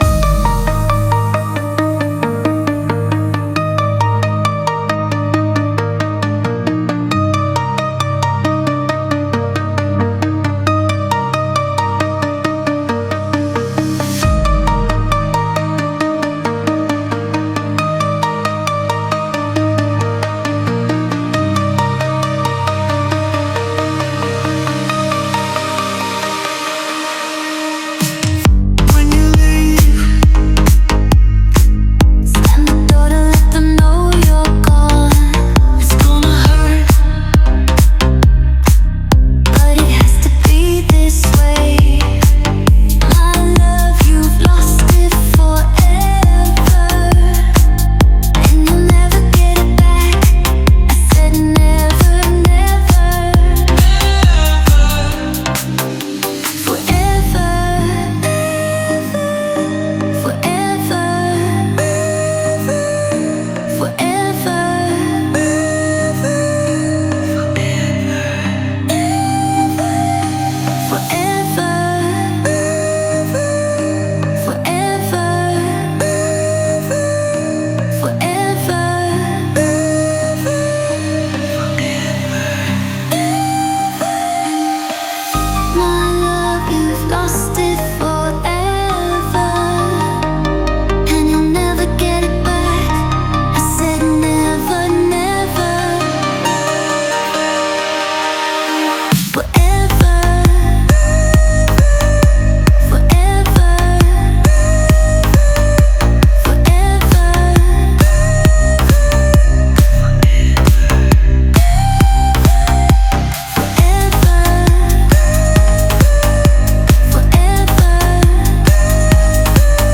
ELETRÔNICAS